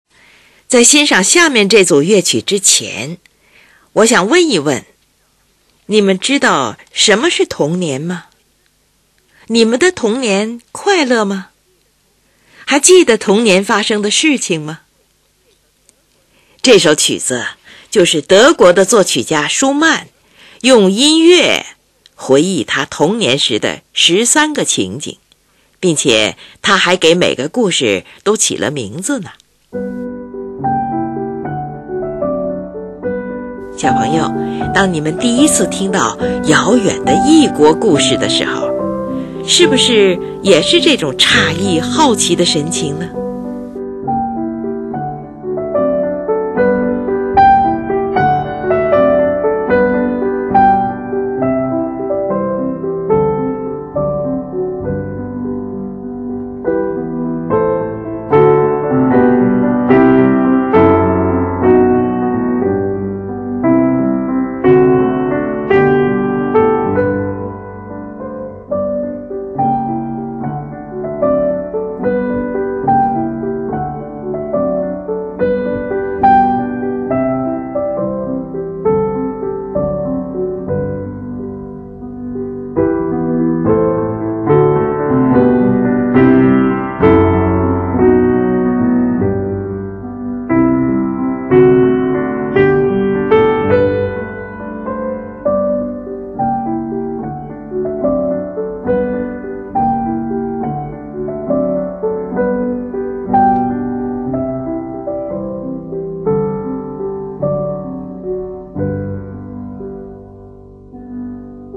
是钢琴艺术史上的一部极为独特的作品。
作品手法精炼形象，刻画生动准确，心理描写逼真，欢快动人，饶有情趣，但也流露出一种因为童年逝去而产生的惆怅感。
这首优美的小曲会使我们回忆起当时那种诧异好奇的神情。